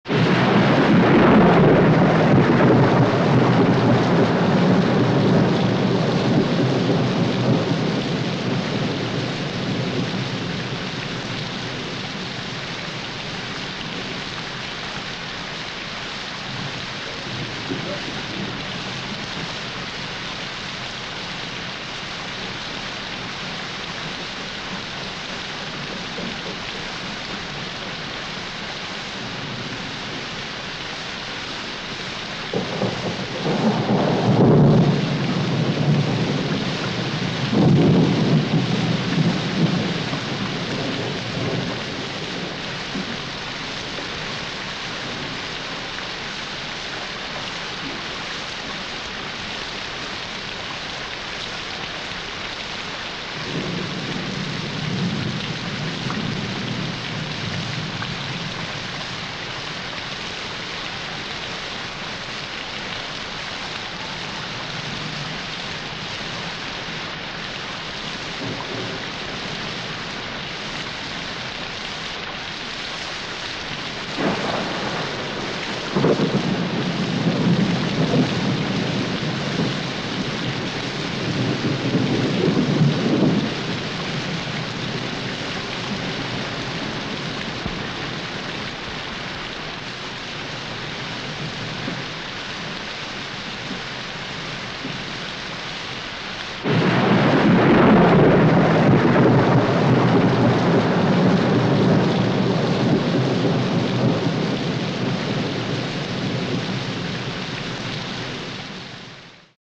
Звуки монстров хижной живности и других страшных тварей существующих и нет...